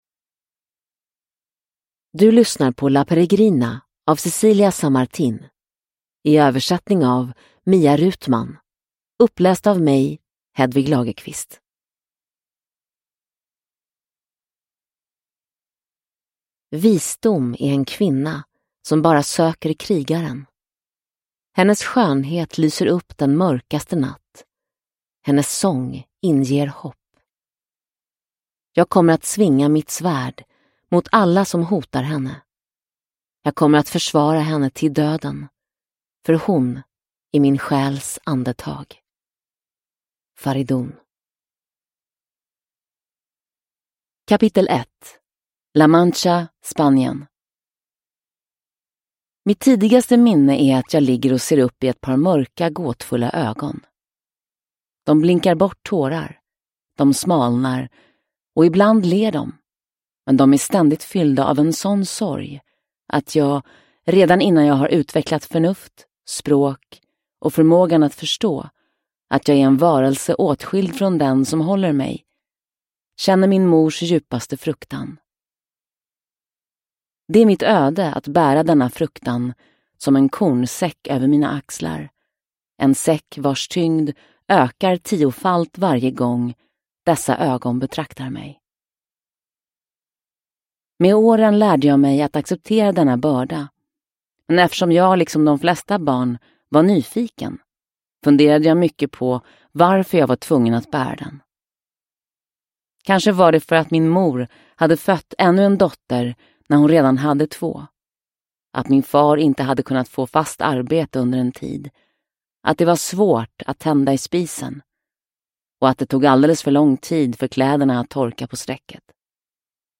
La Peregrina – Ljudbok – Laddas ner